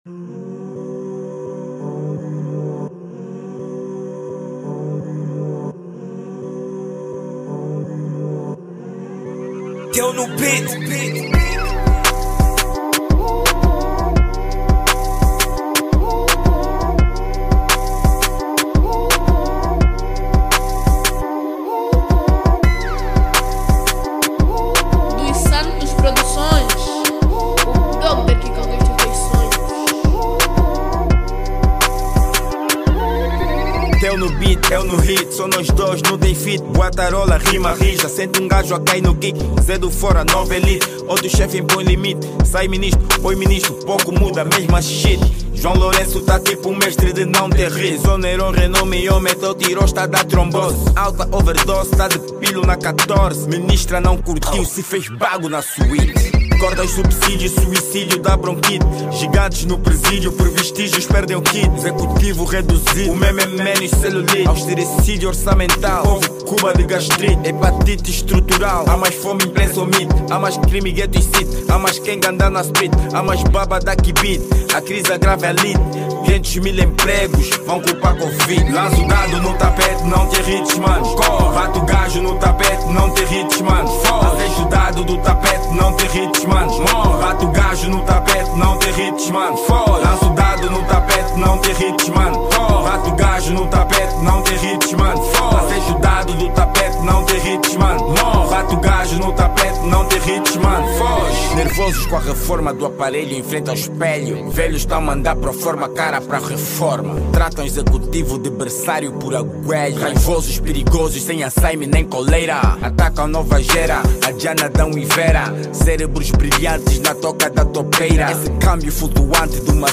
Género: Hip-Hop/ Rap